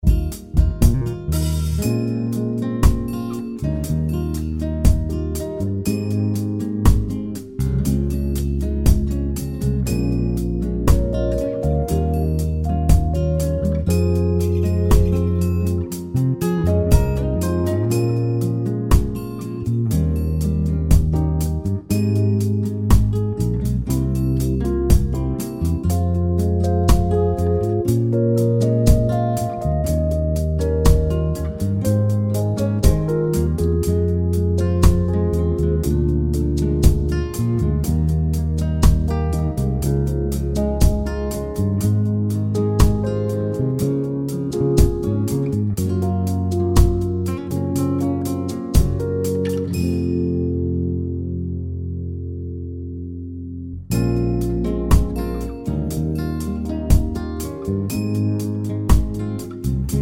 no Backing Vocals Soul / Motown 4:51 Buy £1.50